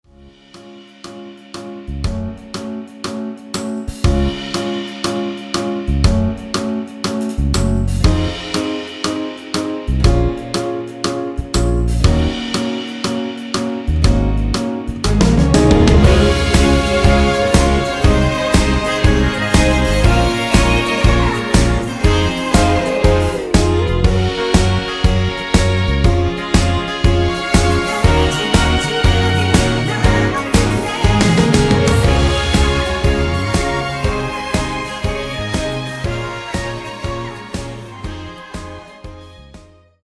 여성분이 부르실 수 있는 키의 MR입니다.
원키에서(+3)올린 코러스 포함된 MR입니다.
C#
앞부분30초, 뒷부분30초씩 편집해서 올려 드리고 있습니다.
중간에 음이 끈어지고 다시 나오는 이유는